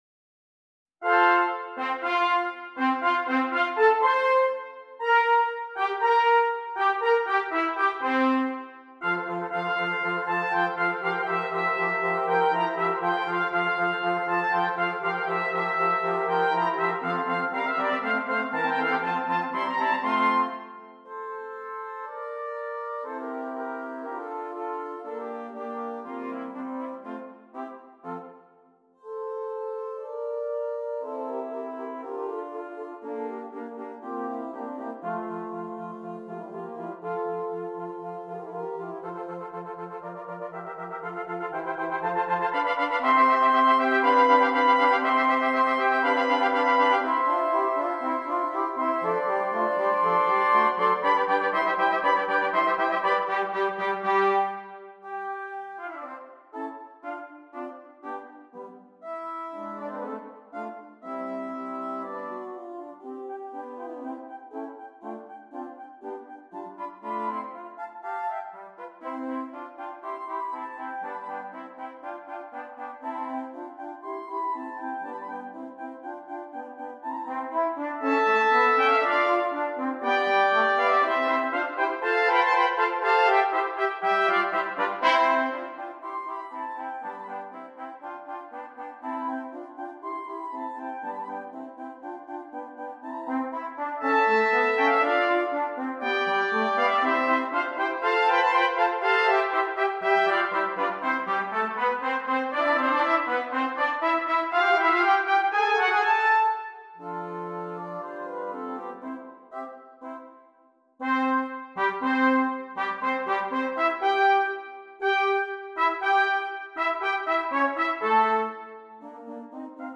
Brass
6 Trumpets
All parts are interesting and exciting.